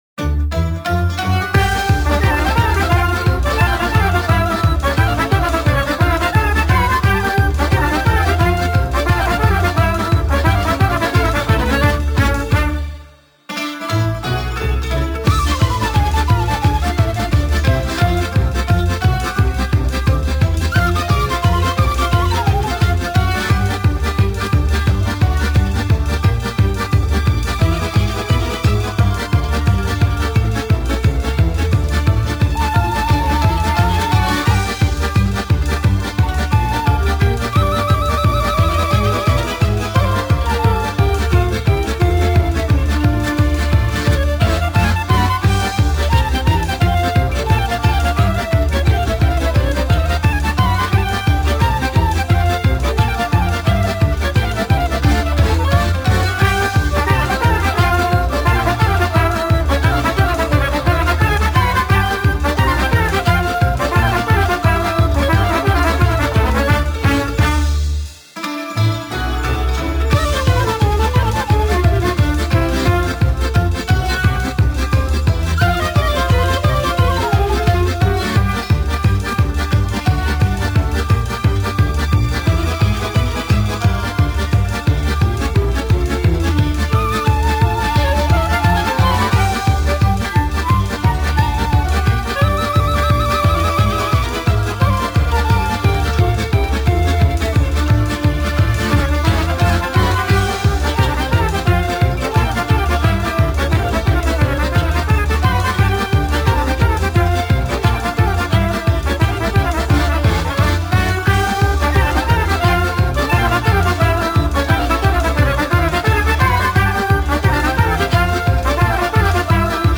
Мінусовка